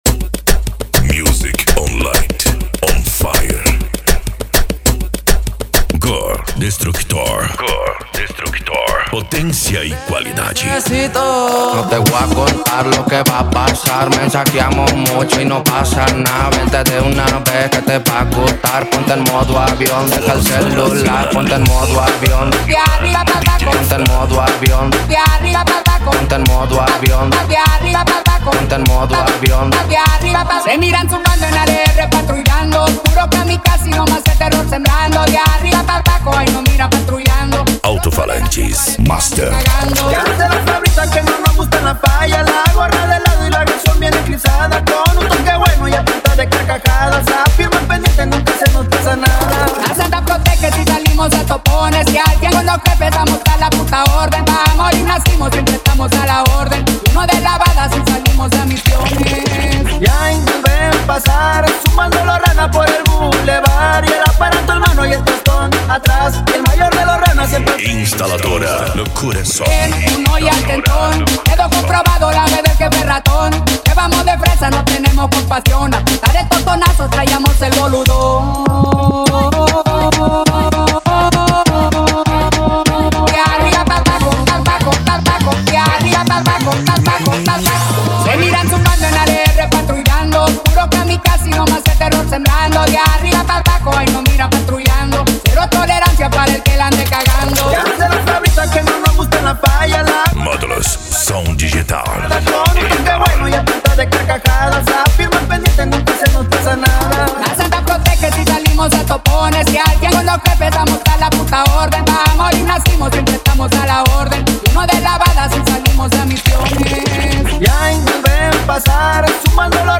Bass
Funk
Remix